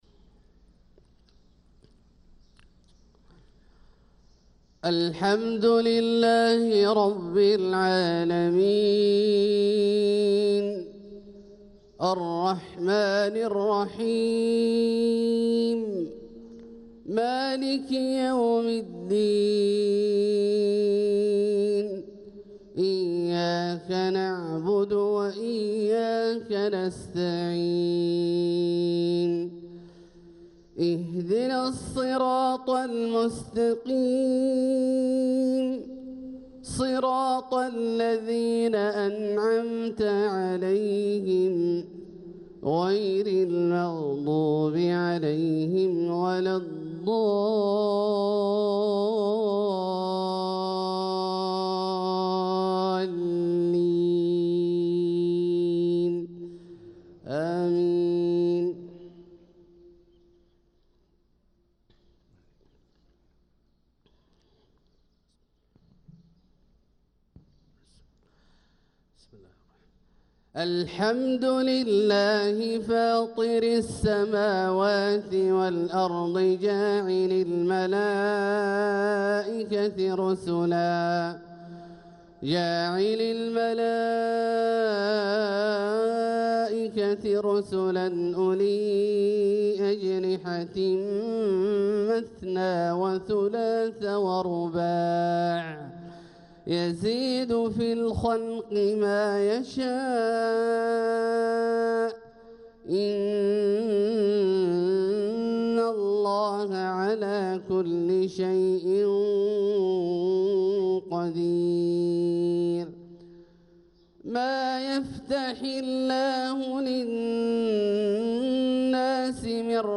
صلاة الفجر للقارئ عبدالله الجهني 2 جمادي الأول 1446 هـ
تِلَاوَات الْحَرَمَيْن .